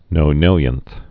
(nō-nĭlyənth)